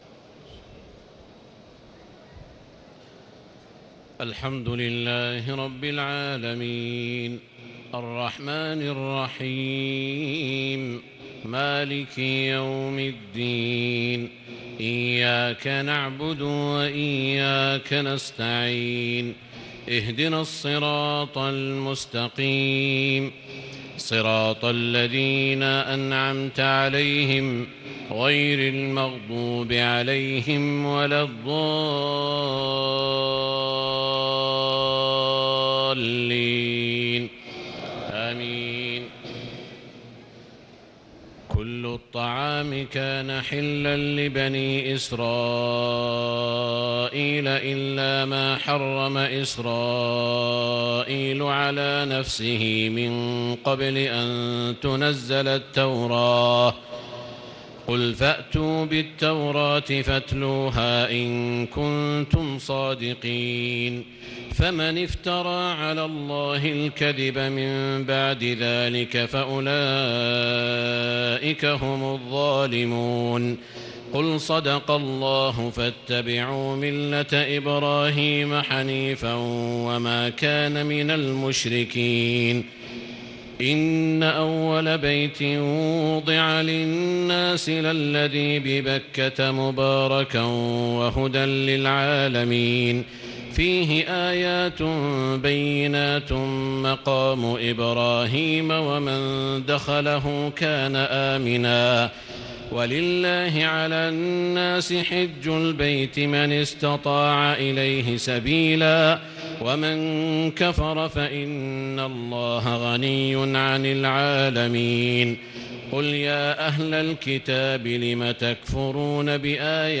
تهجد ليلة 24 رمضان 1438هـ من سورة آل عمران (93-185) Tahajjud 24 st night Ramadan 1438H from Surah Aal-i-Imraan > تراويح الحرم المكي عام 1438 🕋 > التراويح - تلاوات الحرمين